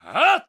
male_skill2.ogg